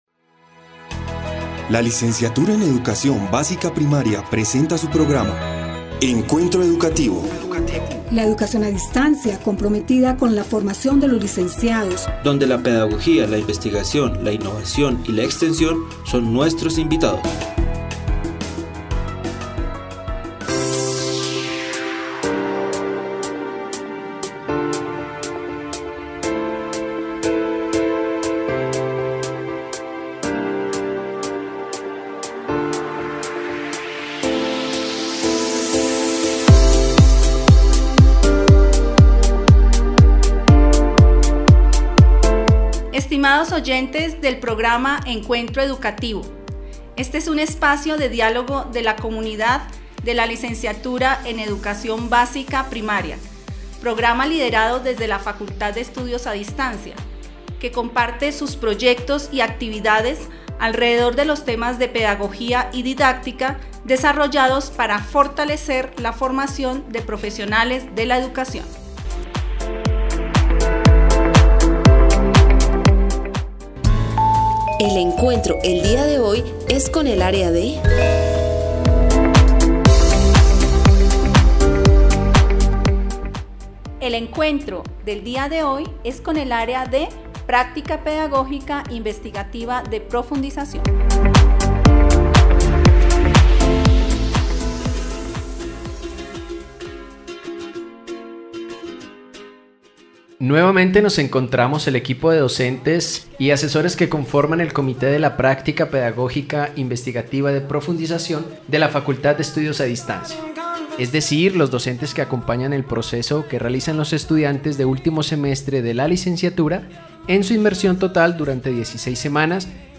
Spa: En el programa radial "Encuentro Educativo", de la Escuela de CienciasHumanísicas y Educación, Progama Licenciatura en Básica Primaria de la Facultad de Estudios a Distancia de la UPTC, el cual se trasmite a través de la emisora FM 104.1, los docentes ponen en contexto el tema sobre la práctica pedagógica investigativa de profundización.